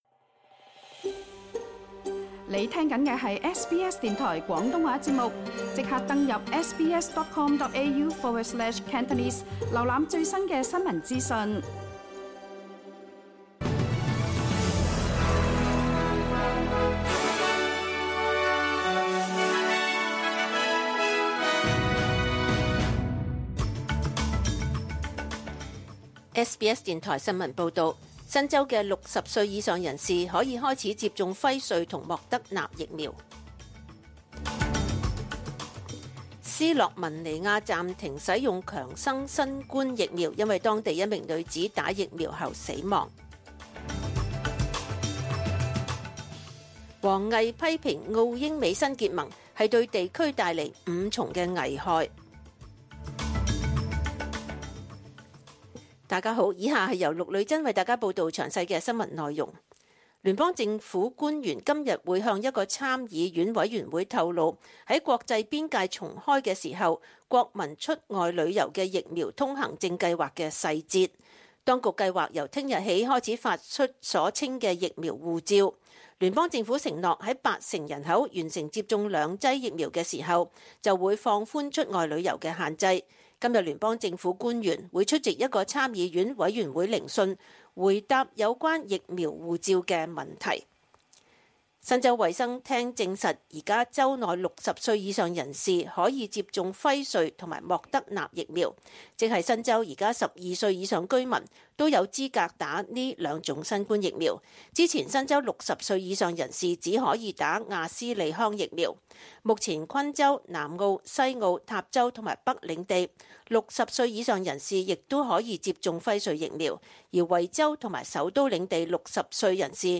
SBS 中文新聞（九月三十日）
SBS 廣東話節目中文新聞 Source: SBS Cantonese